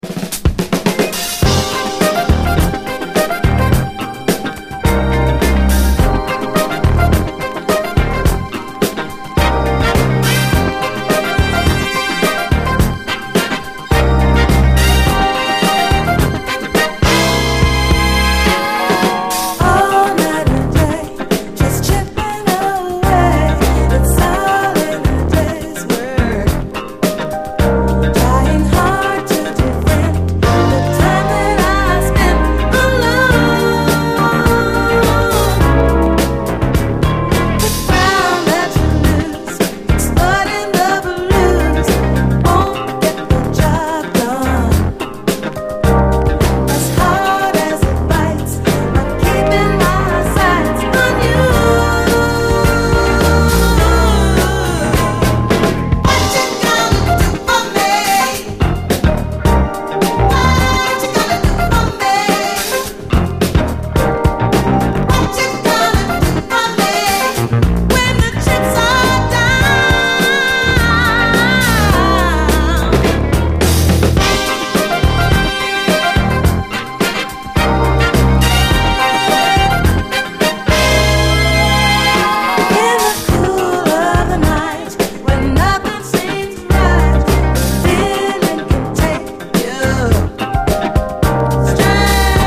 鋭いレアグルーヴ感で切り裂くスリリング・モダン・ソウル
トロピカルなブレイクからレゲエ調な展開を挟むというなかなか珍しいタイプの曲で当店好みなミディアム・ソウル